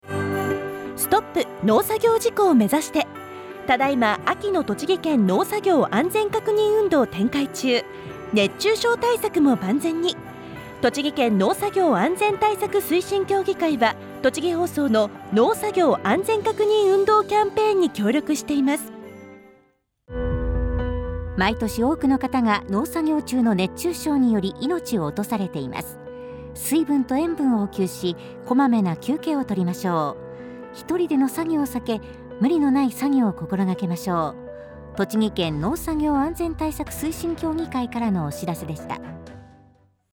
＜CM音源＞ R7秋の農作業安全確認運動キャンペーン_栃木県農作業安全対策推進協議会.mp3 ※構成員：栃木県農業協同組合中央会、全国共済農業協同組合連合会栃木県本部、栃木県農業共済組合、栃木県農業機械商業協同組合、栃木県農業機械士会、栃木県、全国農業協同組合連合会栃木県本部